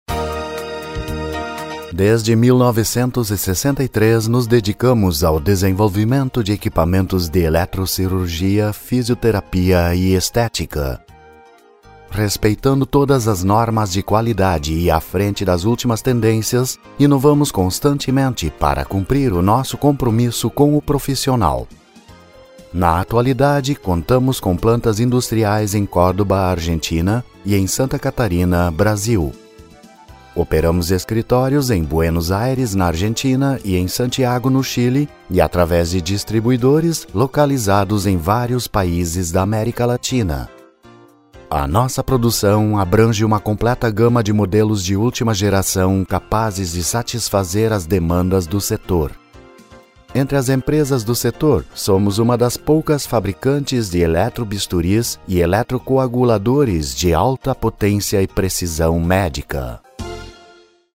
Zauberstimme, deutlich, warm, perfekt, stark
Kein Dialekt
Sprechprobe: Industrie (Muttersprache):